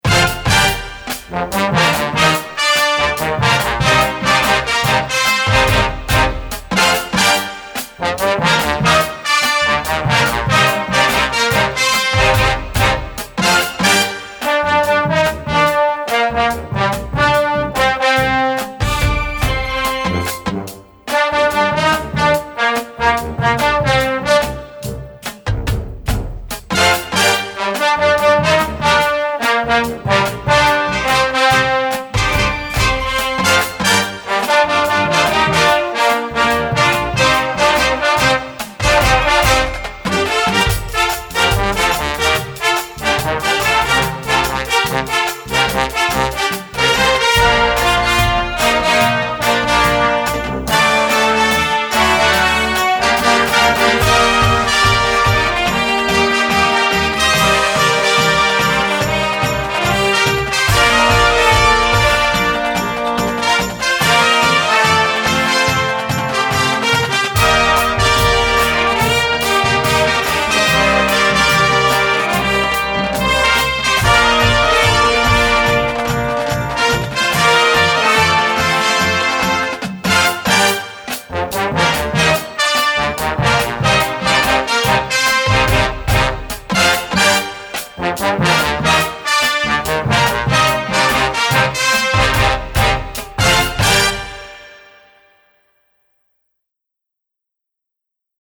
Gattung: Moderner Einzeltitel
Marching-Band
Besetzung: Blasorchester